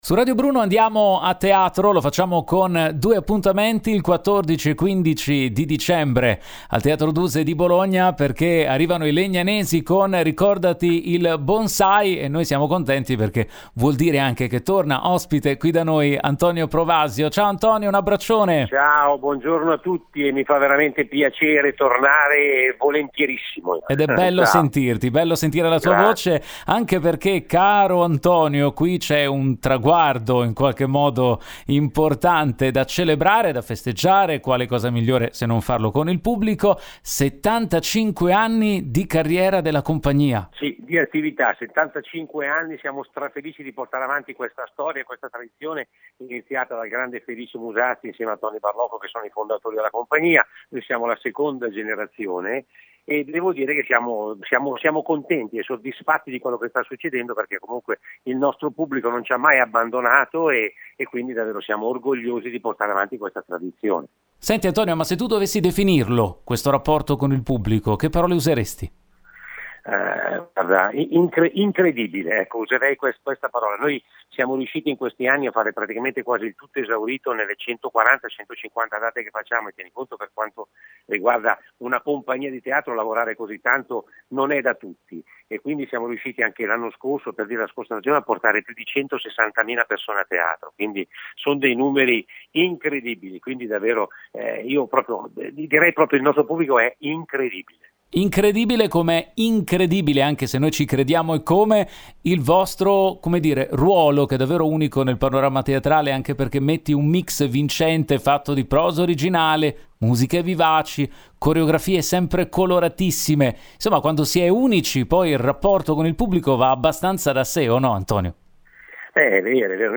Home Magazine Interviste “Ricordati il bonsai” in scena al Teatro Duse di Bologna